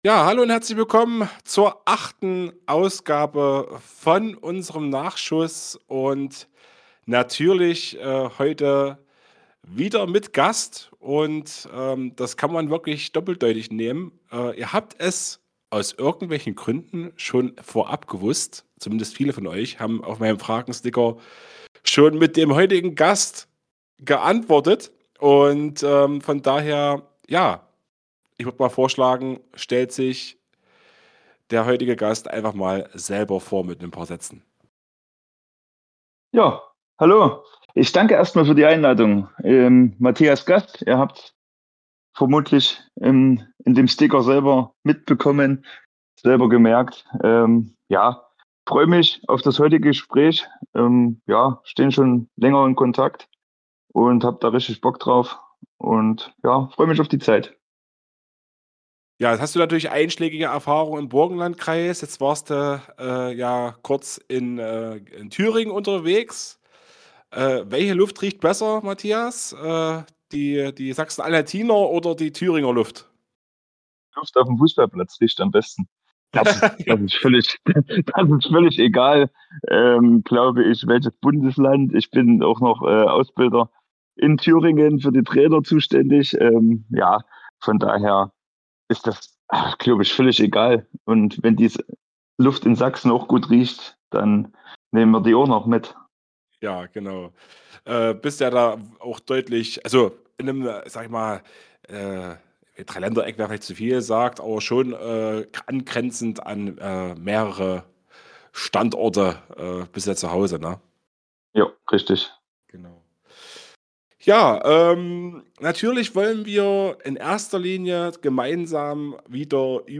der Fußballtalk aus dem Burgenlandkreis Podcast